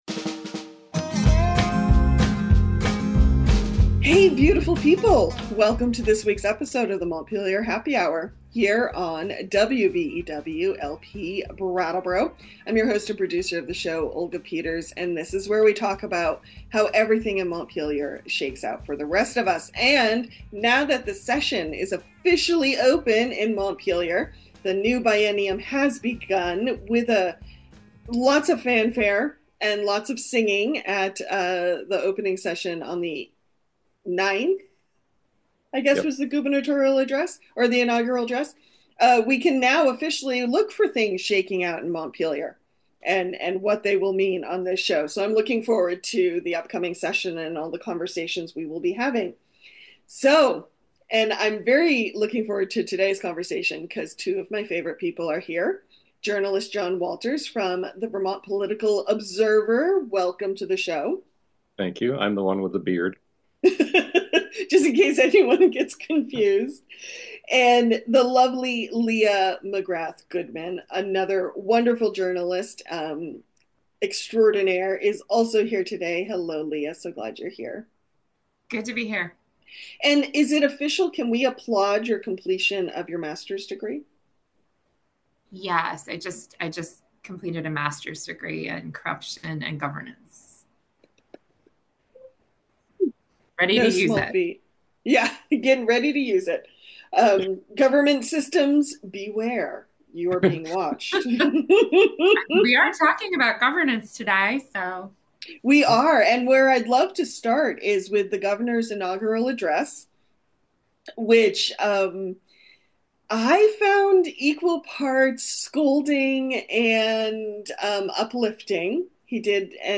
January 10, 2025: Three journalists sit down for a podcast...